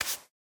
brushing_generic2.ogg